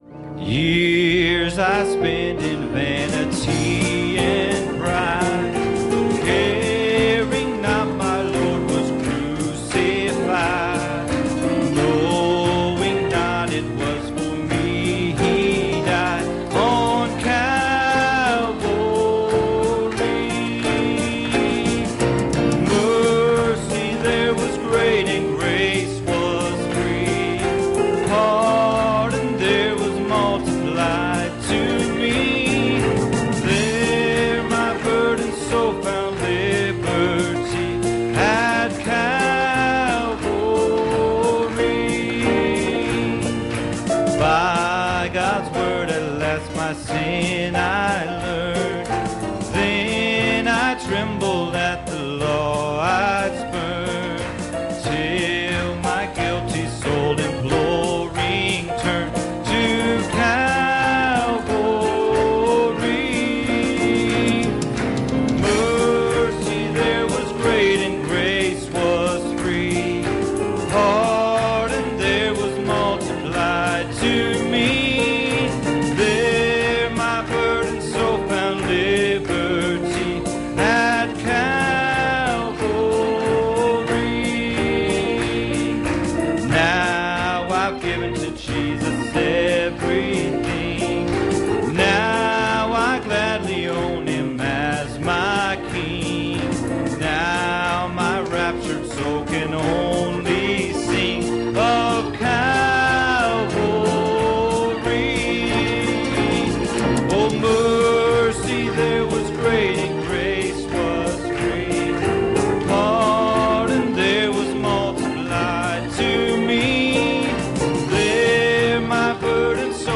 Series: Sunday Morning Services